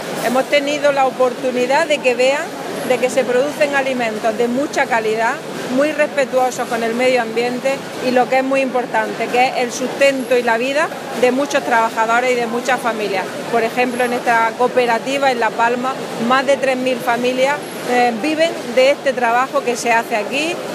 Declaraciones consejera La Palma